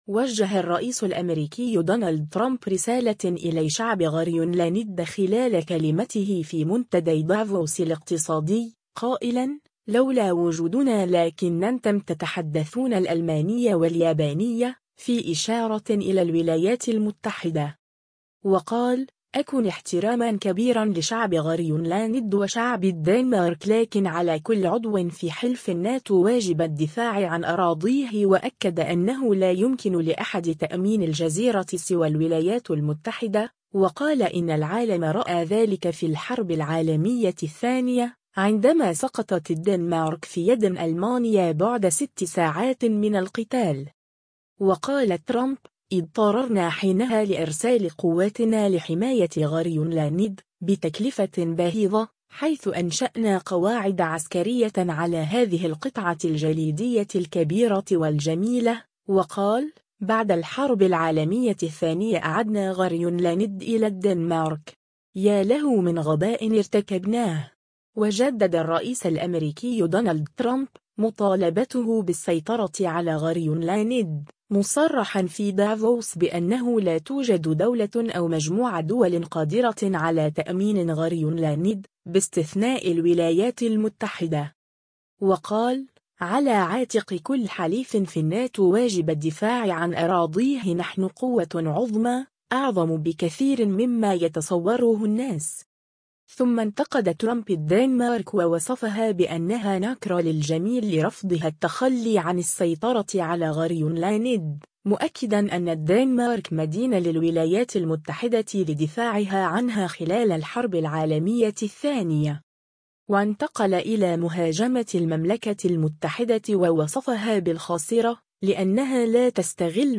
وجه الرئيس الأمريكي دونالد ترامب رسالة إلي شعب غرينلاند خلال كلمته في منتدي دافوس الاقتصادي، قائلا : لولا وجودنا لكنتم تتحدثون الألمانية و اليابانية، في إشارة إلى الولايات المتحدة.